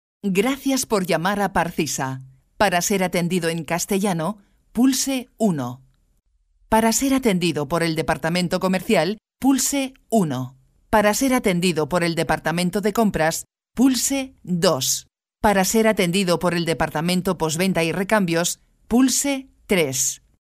Bilingüe español-catalan; voz elegante media; mujer media; locutora española; locutora catalana; Spanish voiceover
Sprechprobe: Sonstiges (Muttersprache):